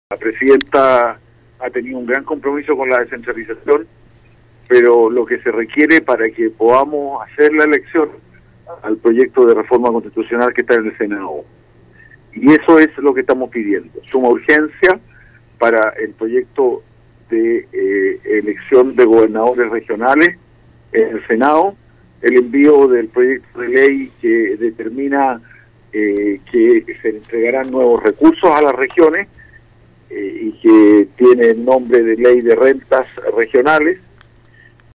El ambiente político también espera con ansias la locución de la mandataria, en este sentido el Diputado Regionalista Rodrigo González, espera propuestas concretas en torno a la descentralización y el aumento a los recursos regionales.